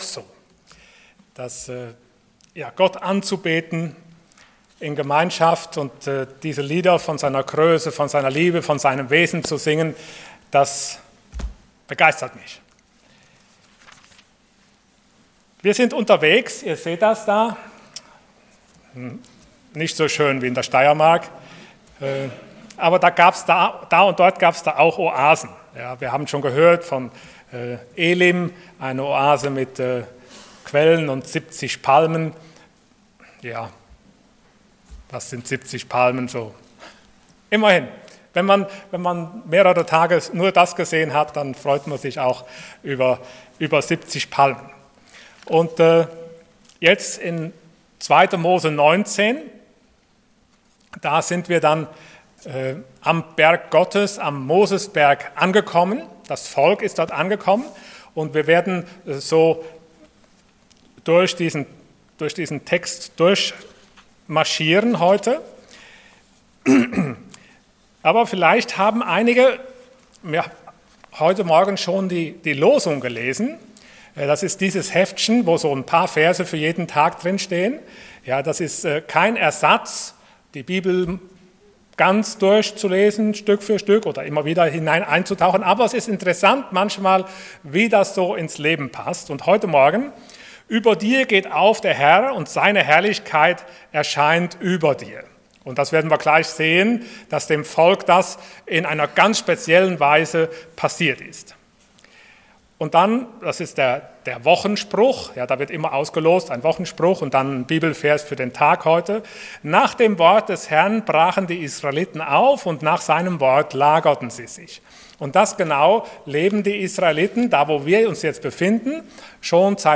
Unterwegs zum Ziel Passage: 2. Mose Dienstart: Sonntag Morgen Die Gottesbegegnung – Wer wird das überleben?